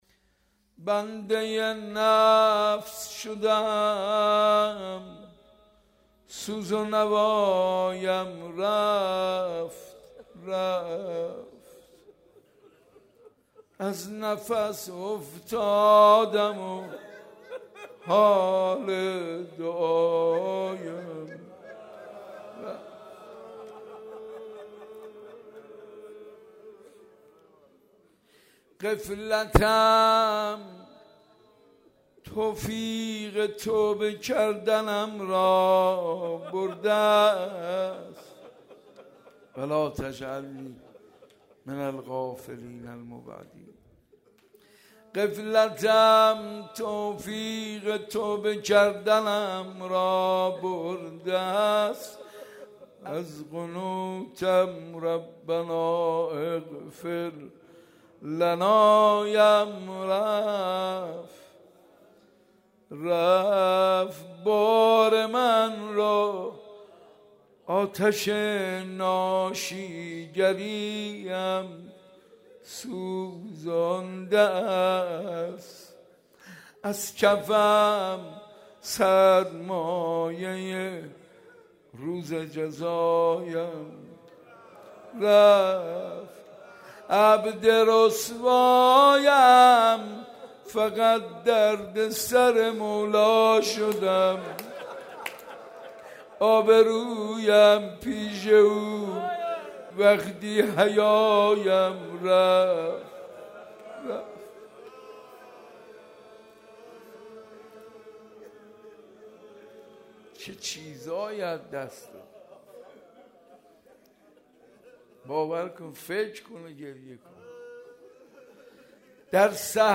حاج منصور ارضی/مراسم هفتگی زیارت عاشور/روضه شهادت حضرت زینب(س)